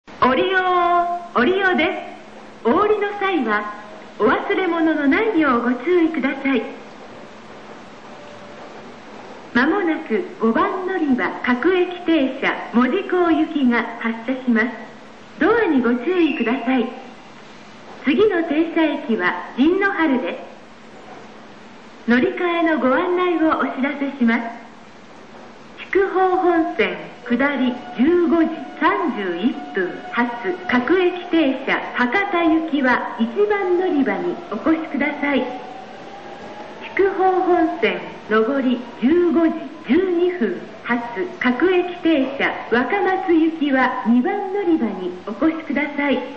旧 ５番のりば到着放送＋発車放送＋乗り換え放送 普通・門司港　(227KB/46秒)
かつての放送ですが…、継ぎ接ぎが酷いです。